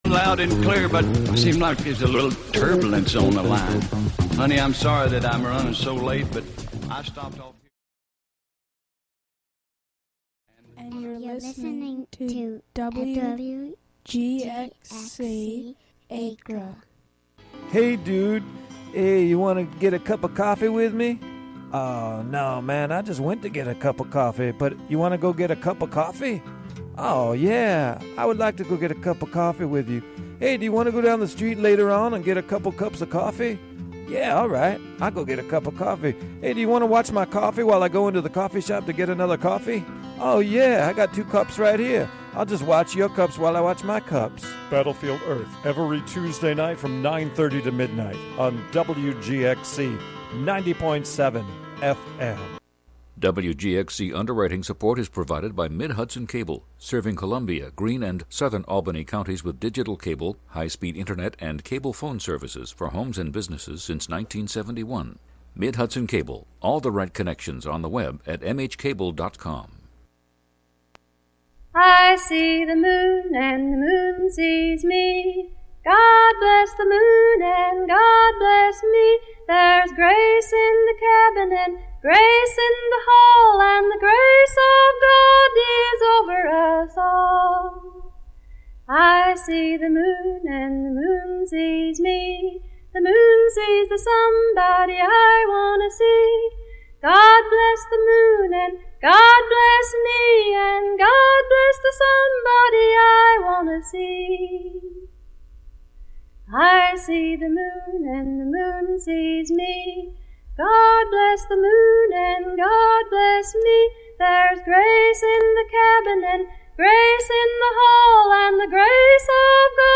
You’re invited to put on your boots and join us every Friday morning as we meander through the wild areas of our modern urban landscape, exploring contemporary and classic Americana, folk, country and elusive material that defies genre.
Broadcast live from the Hudson studio.